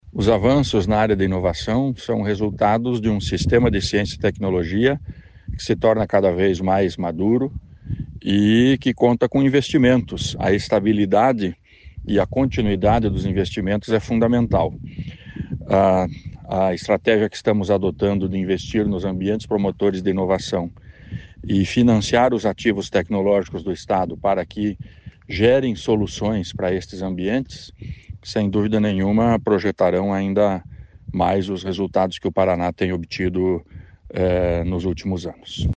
Sonora do secretário Estadual da Ciência, Tecnologia e Ensino Superior, Aldo Bona, sobre o 3º lugar no Índice Brasil de Inovação e Desenvolvimento | Governo do Estado do Paraná